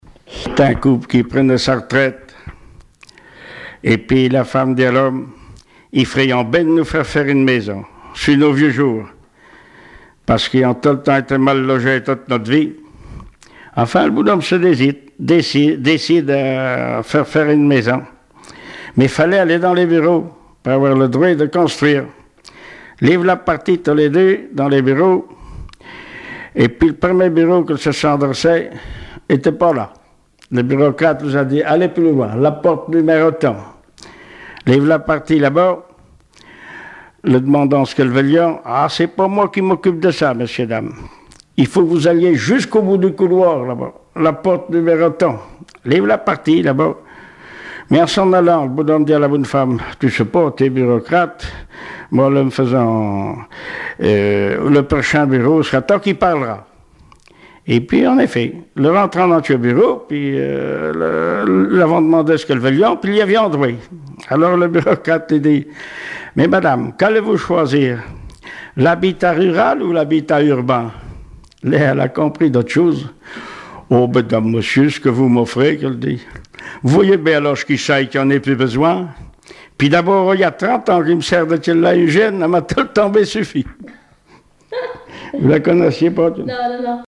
Langue Patois local
Genre sketch
Témoignages et chansons traditionnelles et populaires